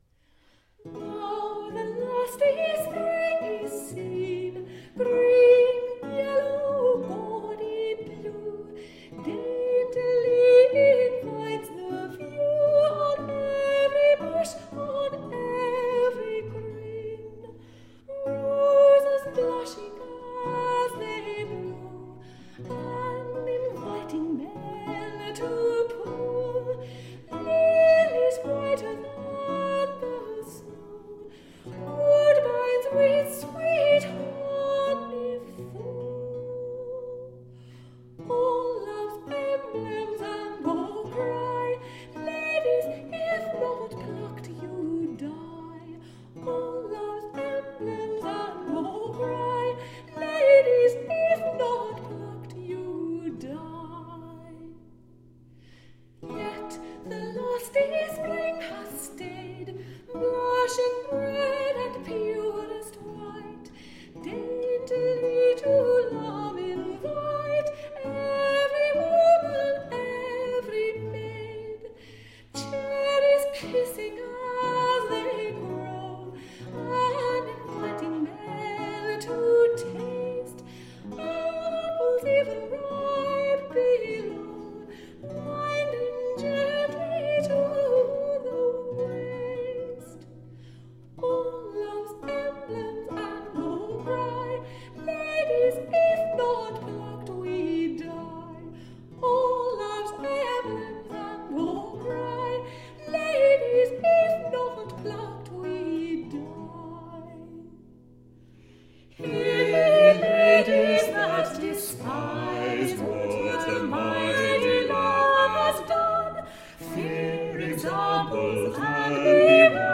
Traditional early english music.